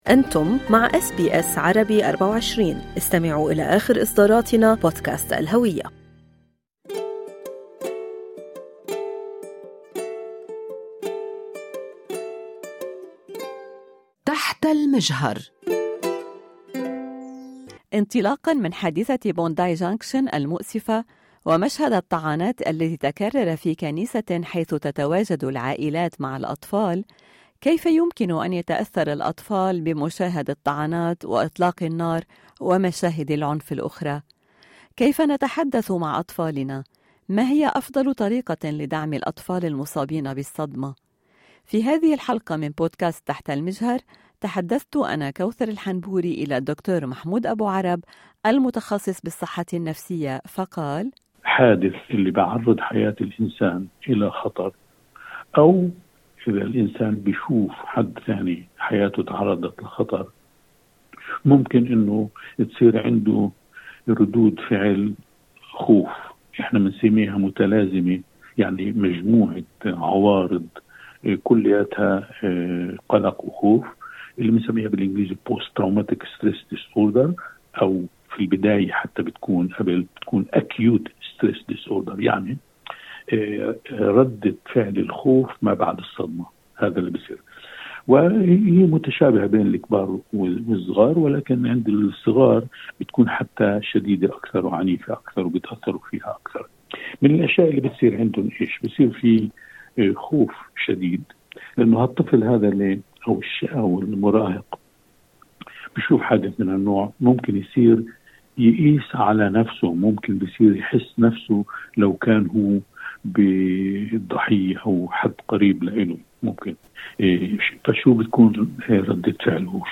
كيف نحمي أطفالنا من مشاهد العنف؟:طبيب نفسي يشرح